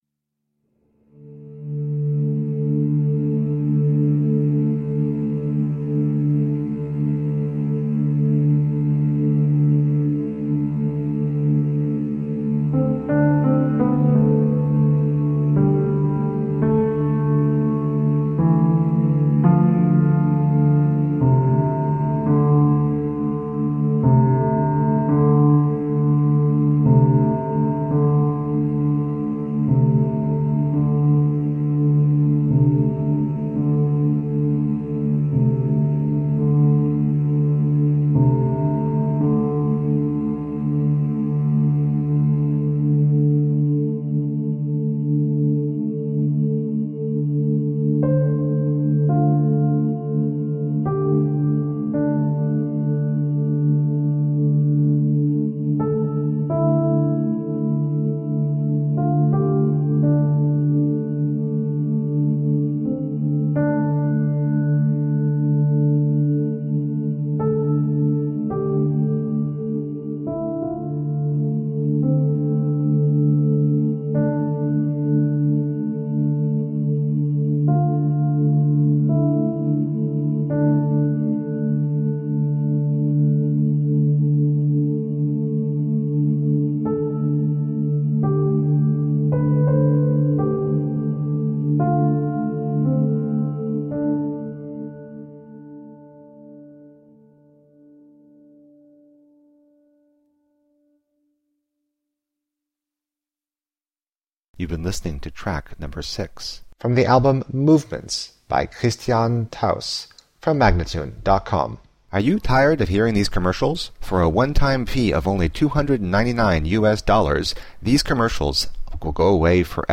Collections of melancholic and minimalistic soundscapes.